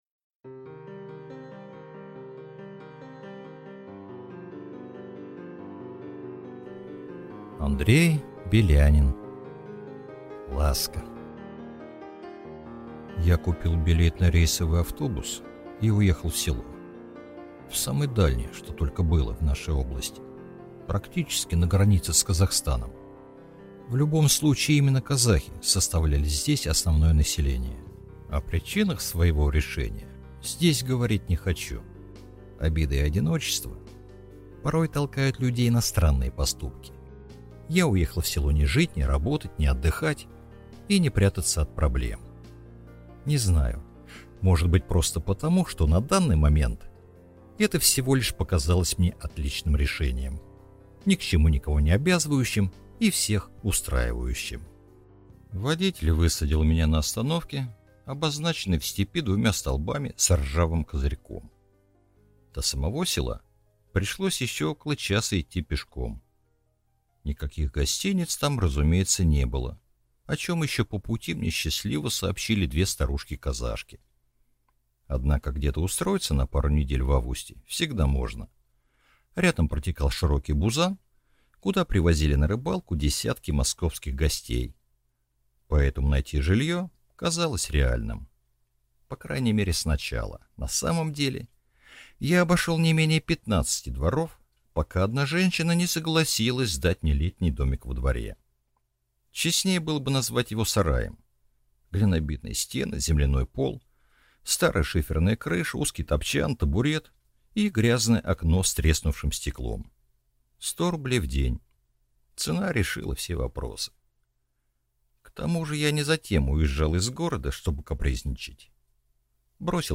Аудиокнига Ласка | Библиотека аудиокниг